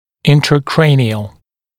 [ˌɪntrə’kreɪnɪəl][ˌинтрэ’крэйниэл]внутричерепной